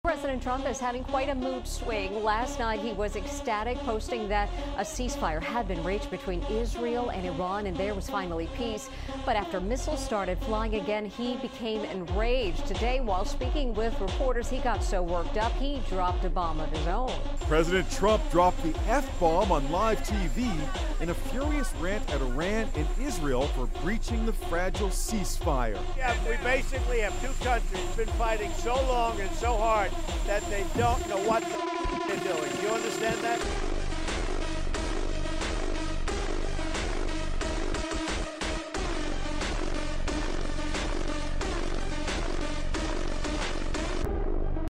Trump drops the F b*mb on live tv